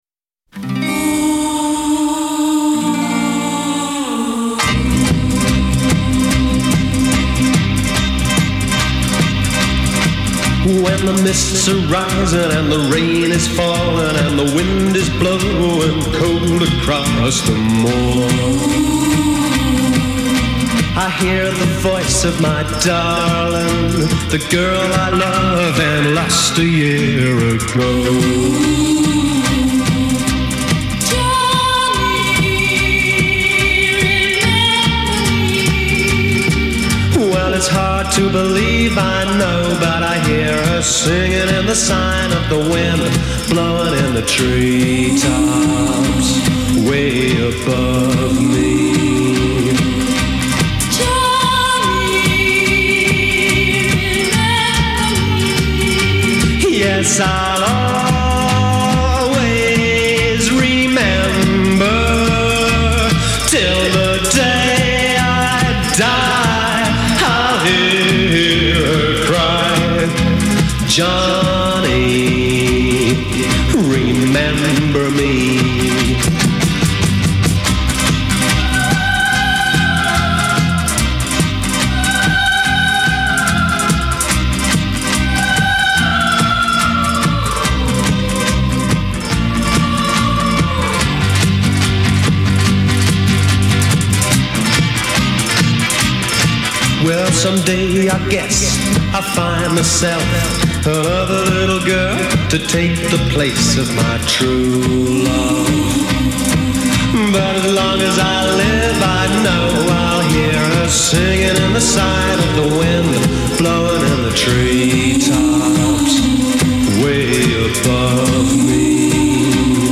a gallon of reverb, a ghostly female wail